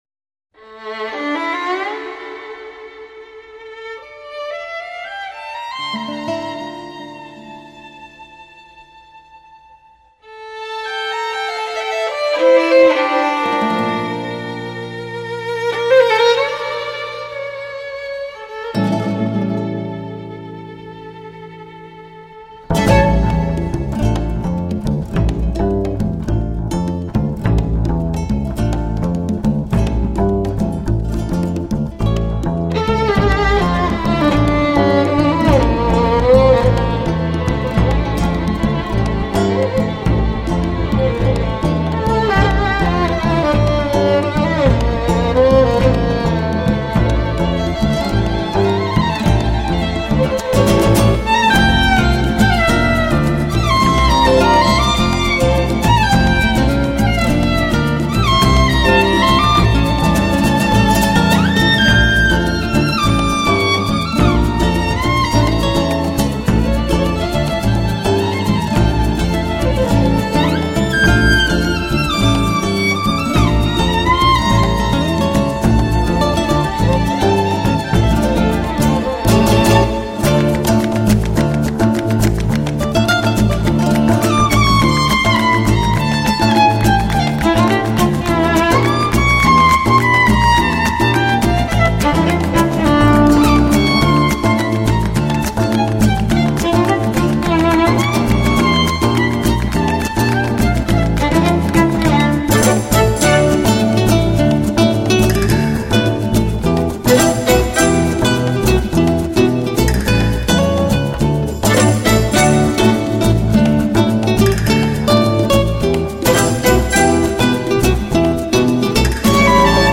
小提琴--卡门口红